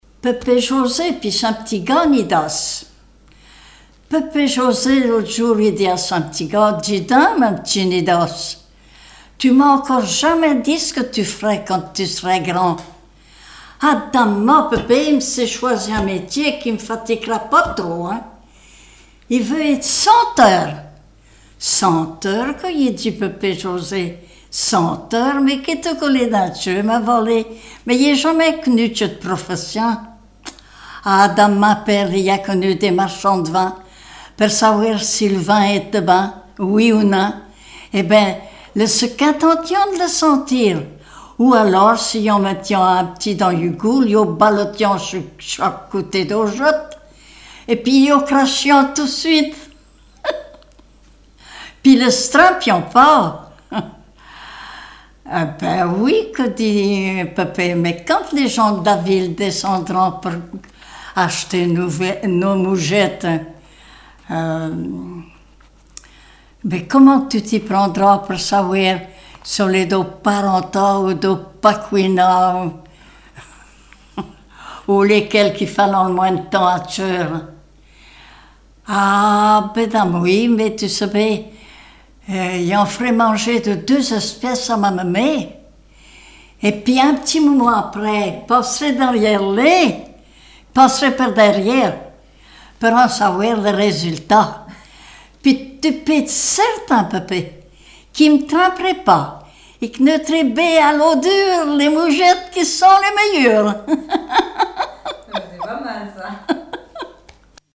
Mémoires et Patrimoines vivants - RaddO est une base de données d'archives iconographiques et sonores.
Genre sketch
Catégorie Récit